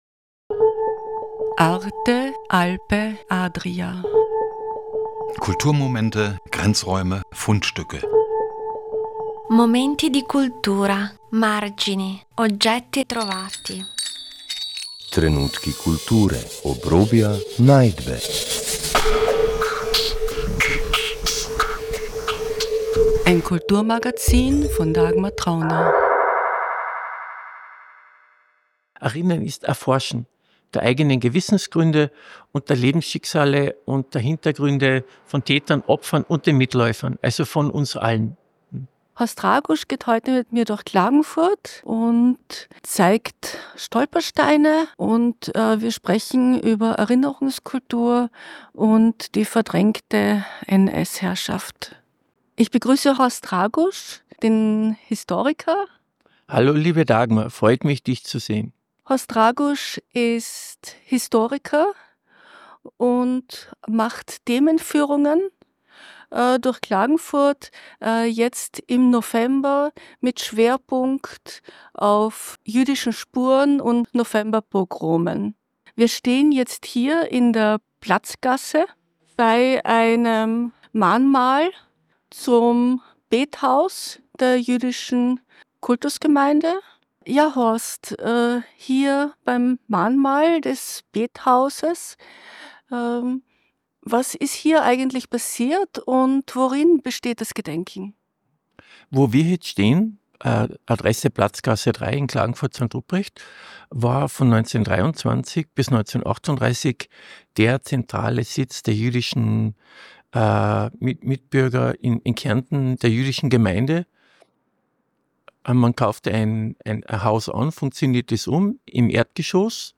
Stationen des akustischen Rundganges sind u.a.: Das ehemalige jüdische Bethaus in der Platzgasse 3, nun ein Mahnmal, errichtet von der israelitischen Kultusgemeinde.